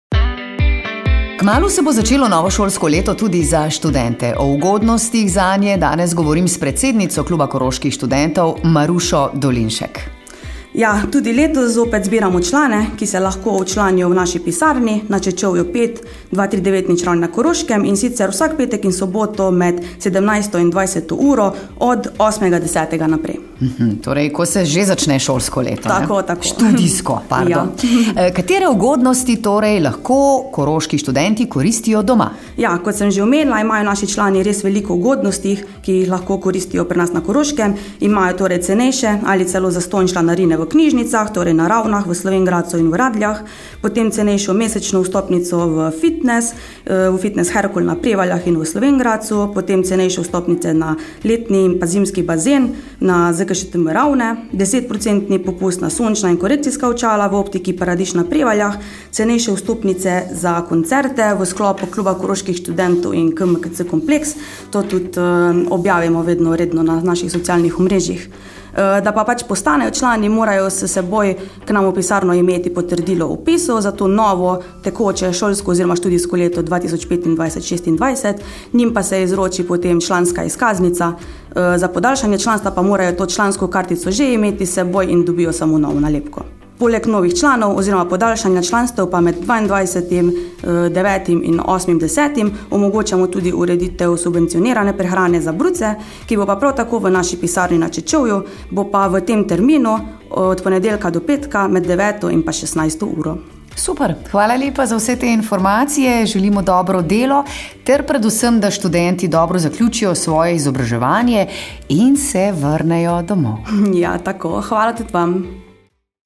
pogovor KKS 2.mp3